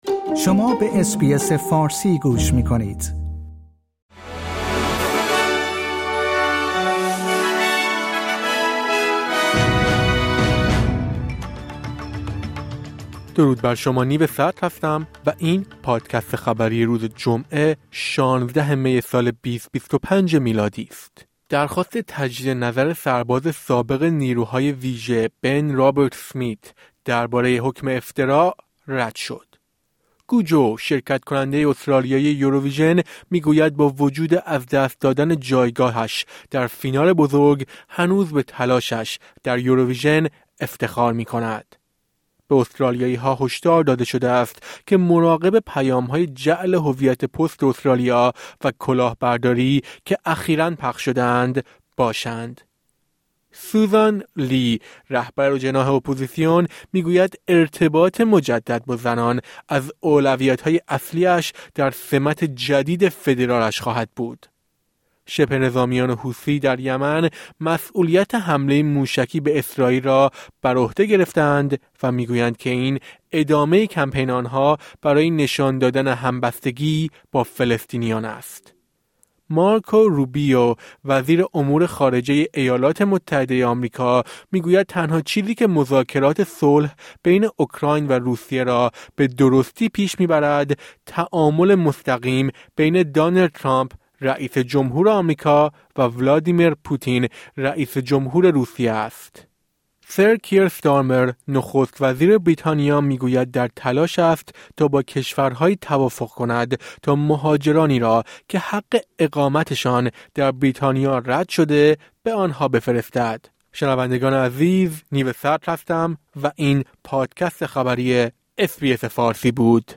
در این پادکست خبری مهمترین اخبار امروز جمعه ۱۶ می ارائه شده است.